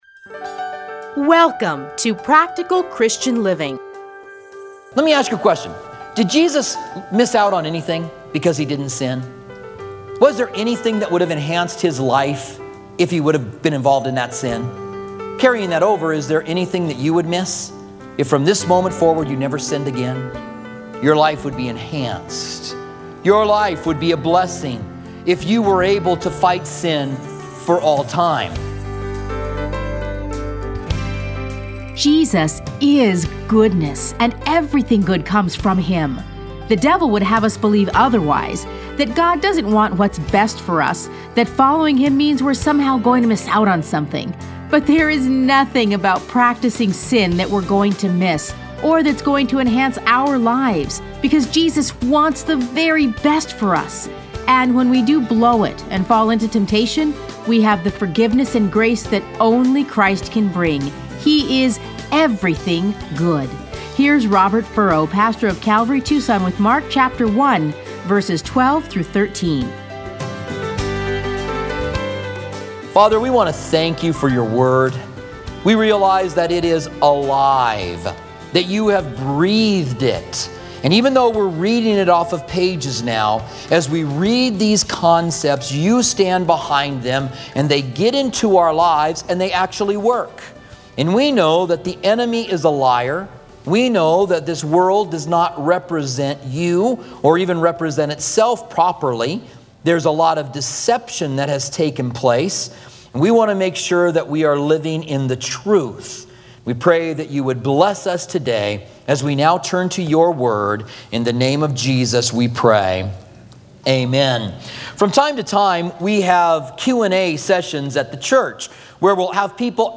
Listen to a teaching from Mark 1:12-13.